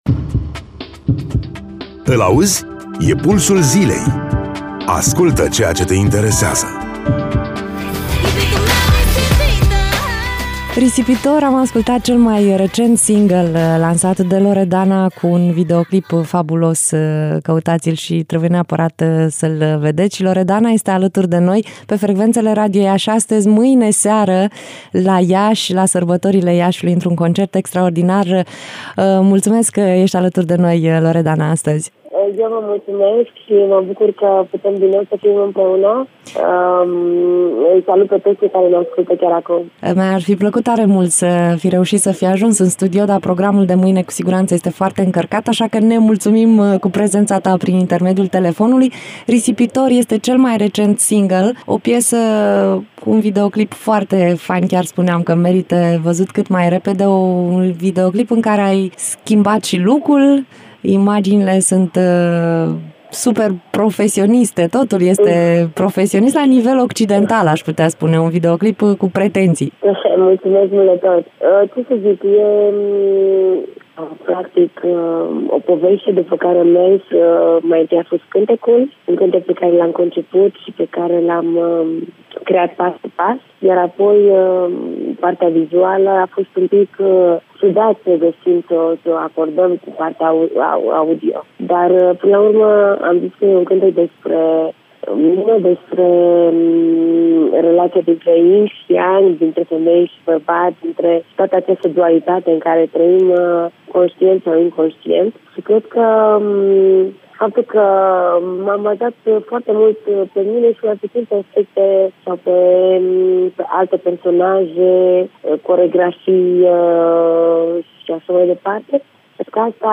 14-Oct-Interviu-Loredana.mp3